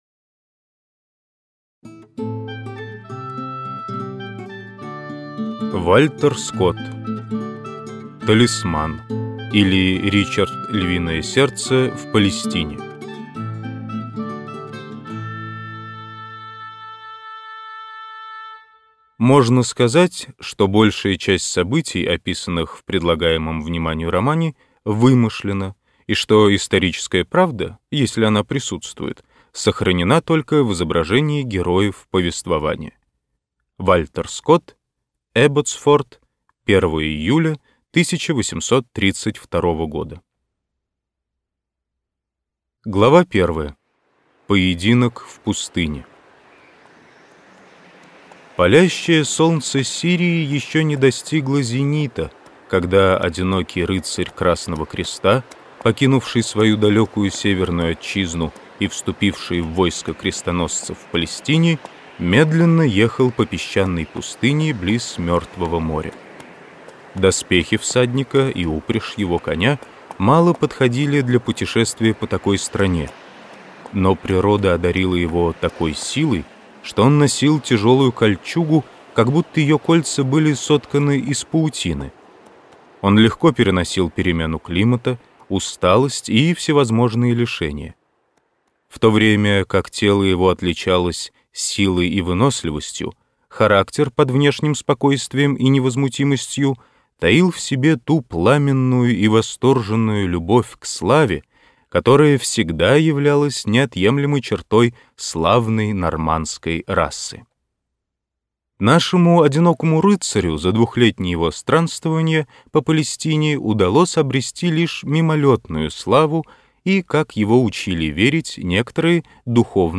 Аудиокнига Талисман, или Ричард Львиное сердце в Палестине - купить, скачать и слушать онлайн | КнигоПоиск